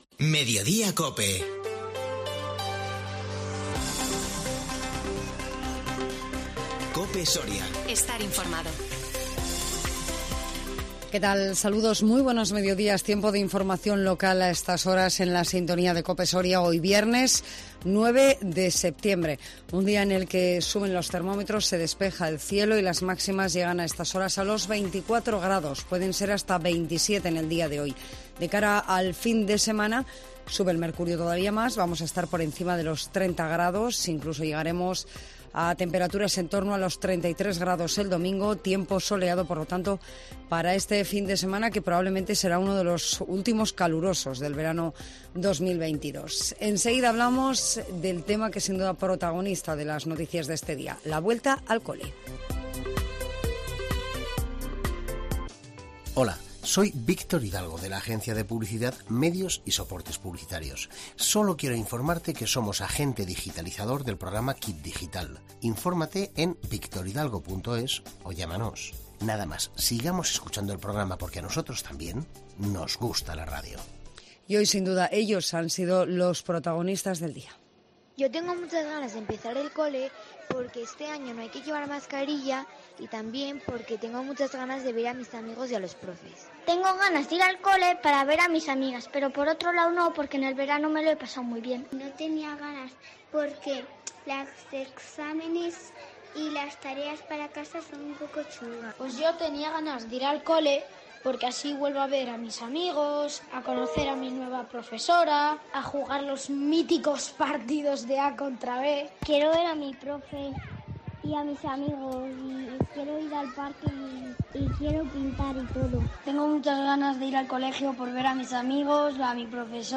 INFORMATIVO MEDIODÍA COPE SORIA 9 SEPTIEMBRE 2022